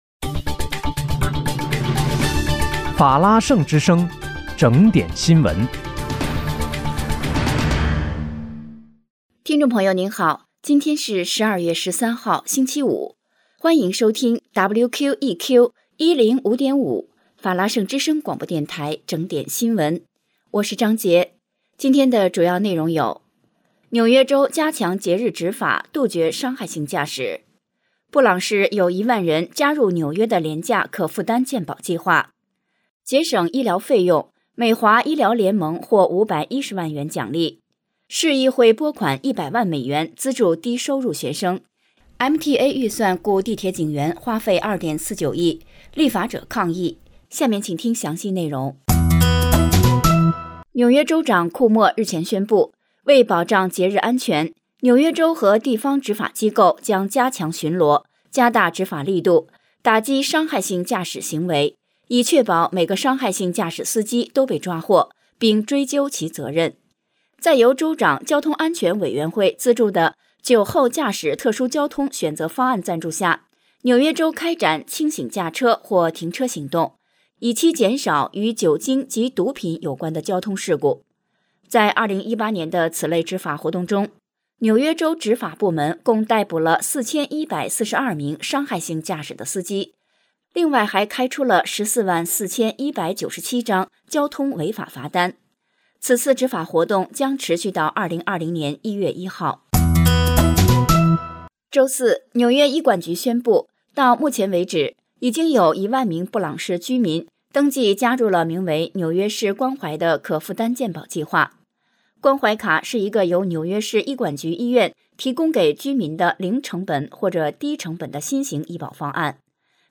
12月13日（星期五）纽约整点新闻